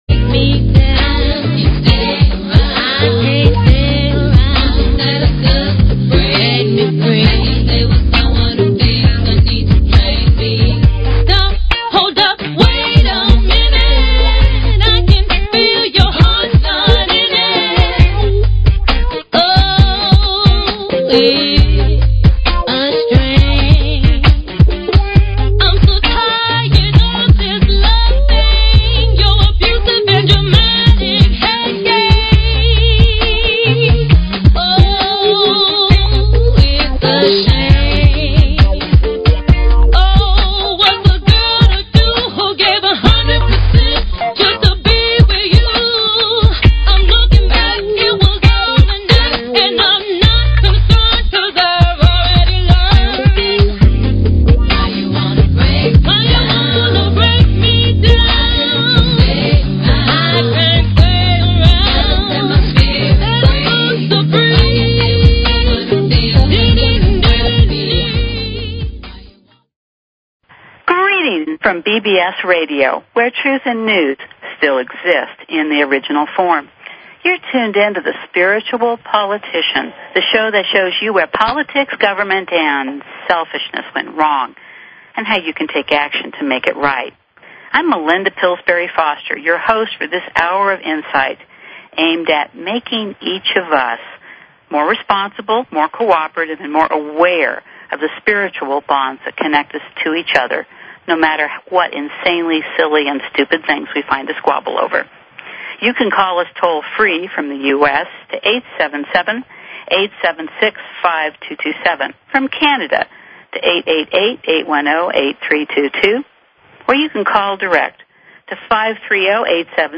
Talk Show Episode, Audio Podcast, Spiritual_Politician and Courtesy of BBS Radio on , show guests , about , categorized as